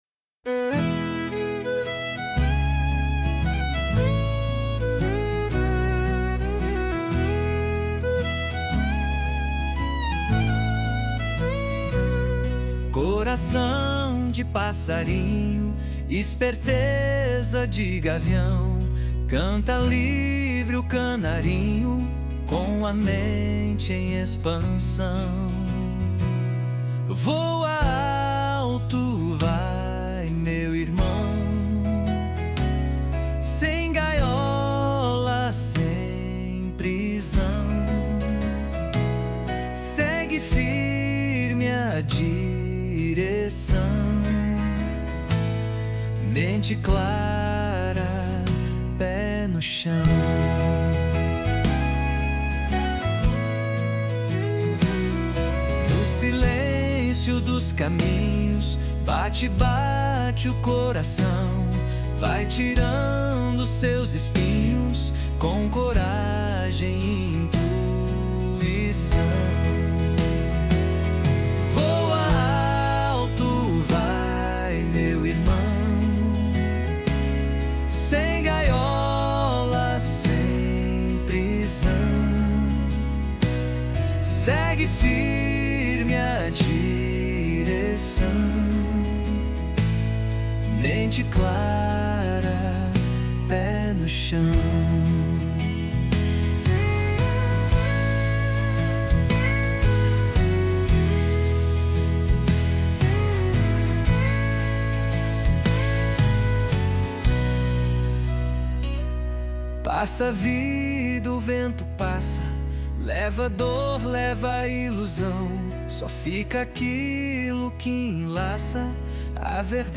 Agora, além de lê-lo, você pode também ouvir sua versão musicada, criada com carinho para transformar palavras em melodia.